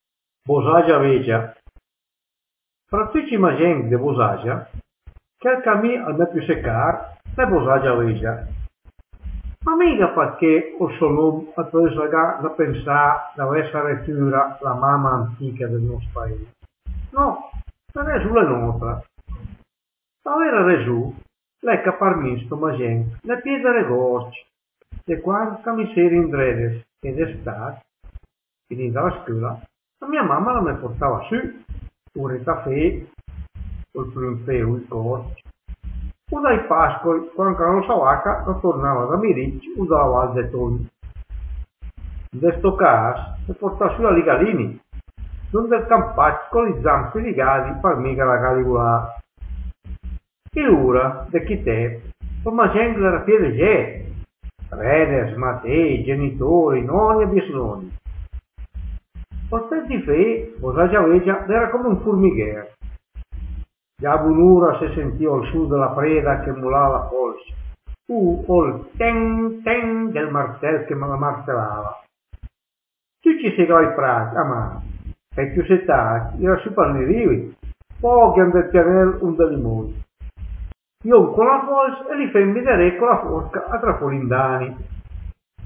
Bośàgia Végia | Dialetto di Albosaggia